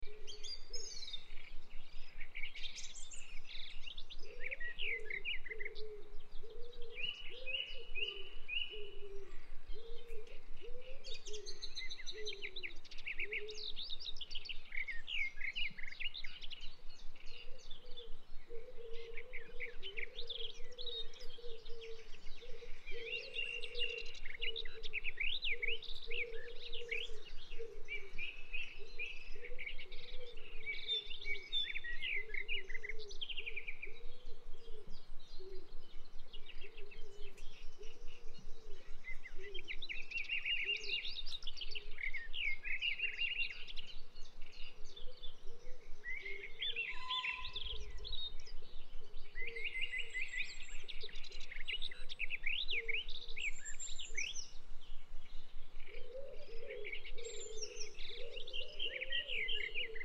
Bird Park 03 Bouton sonore